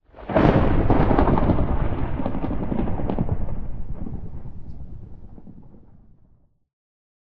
thunder2.ogg